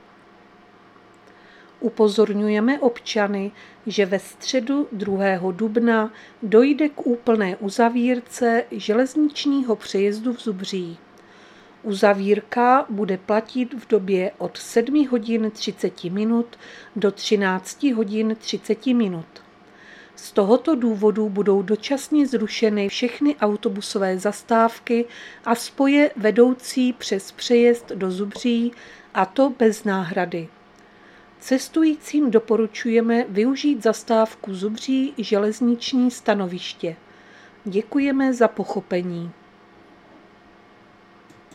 Záznam hlášení místního rozhlasu 31.3.2025
Zařazení: Rozhlas